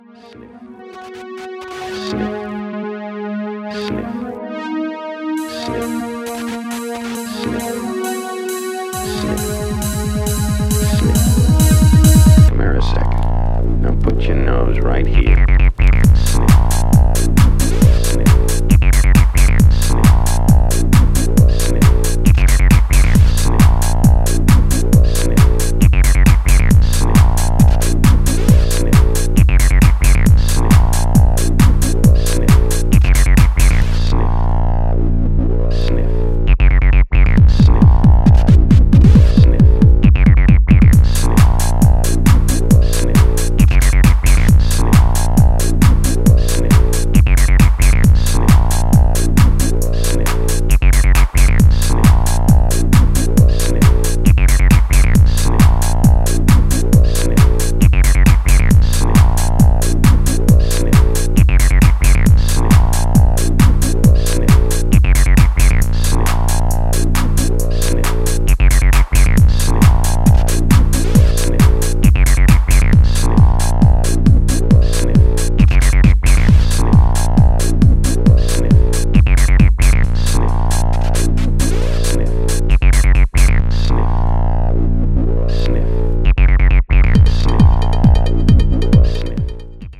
5 tried & tested dancefloor cuts.
Electro House Techno